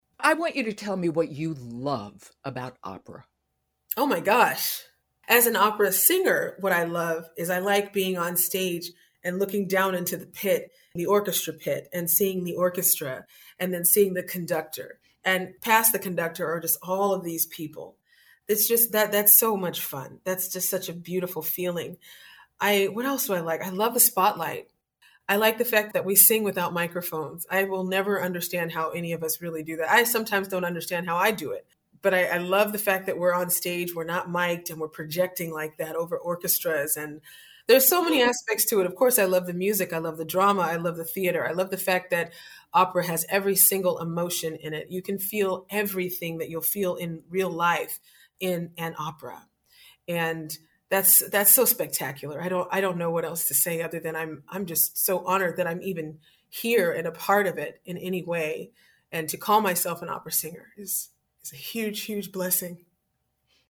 Teaser